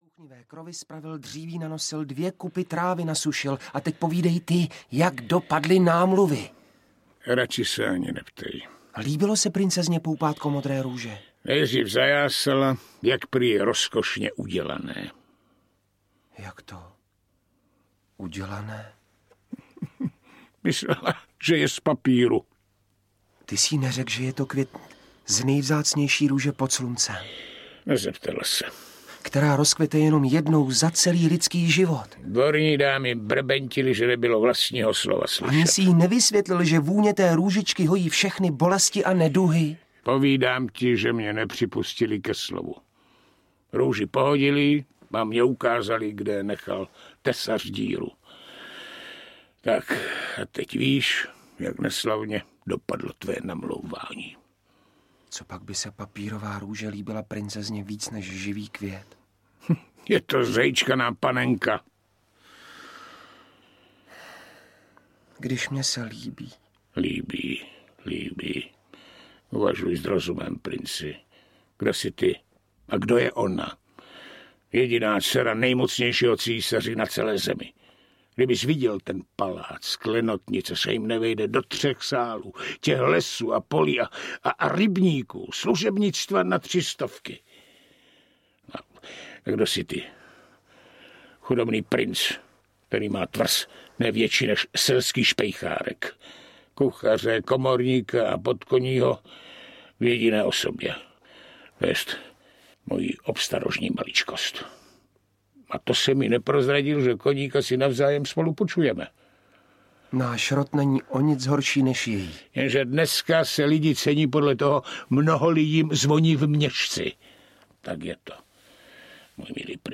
Malá mořská víla a další tři pohádky audiokniha
Ukázka z knihy